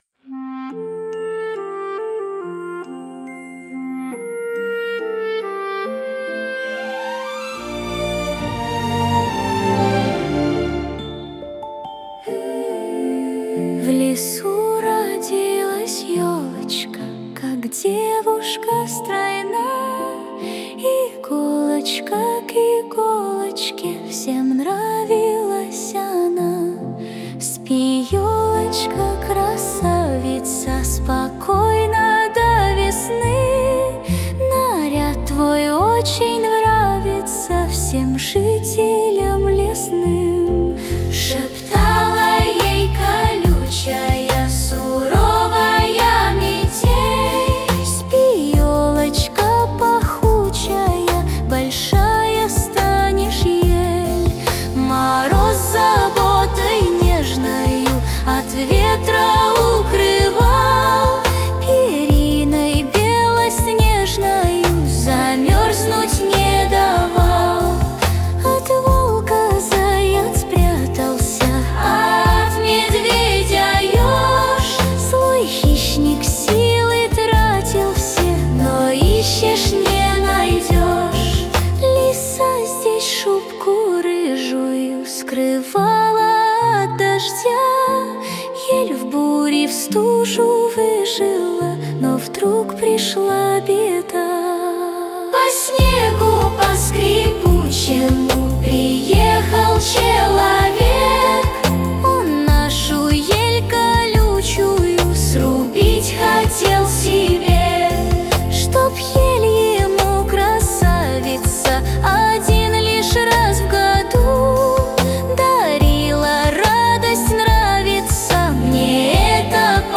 новогодней песенки
Правда, у нас она вышла более серьёзной и не такой детской.